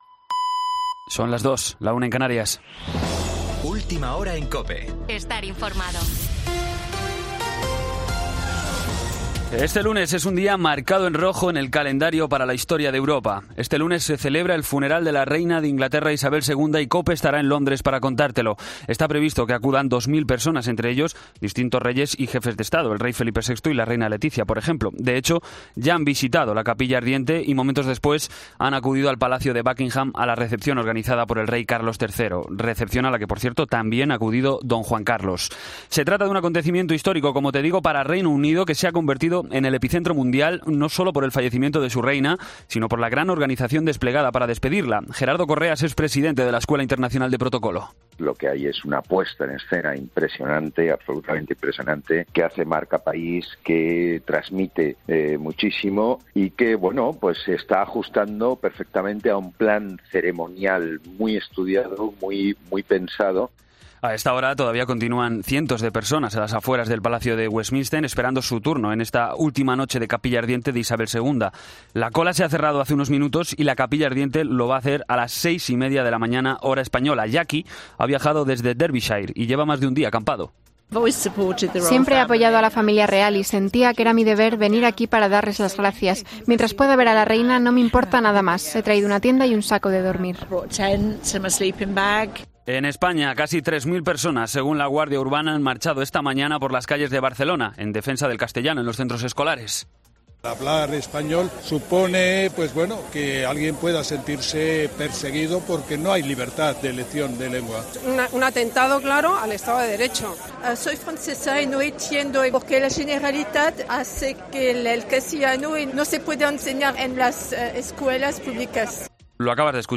Boletín de noticias COPE del 19 de septiembre a las 02:00 hora